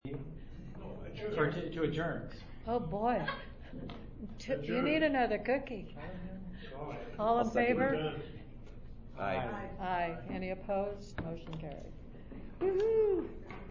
White City Council Meeting
Meeting